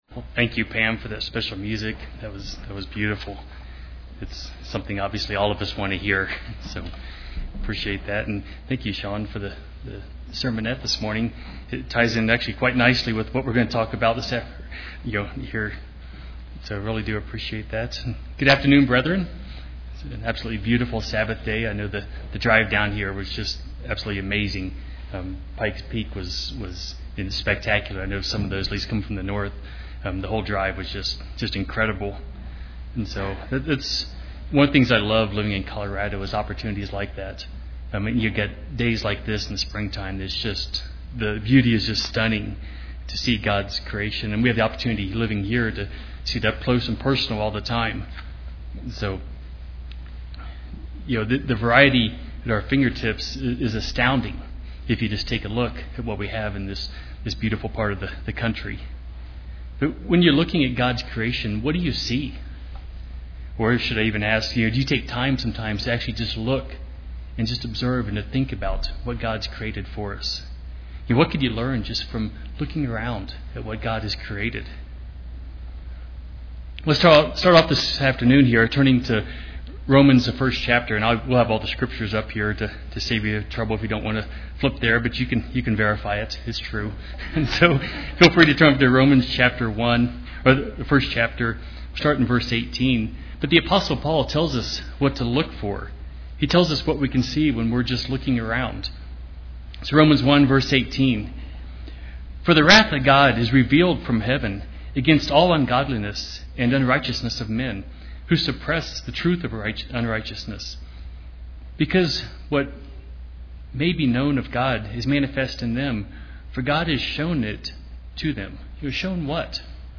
The night before he was taken captive by Roman soldiers, He uses some analogies in His teaching. This sermon will explain those analogies and the lessons for His disciples as well as lessons for us today.
Given in Colorado Springs, CO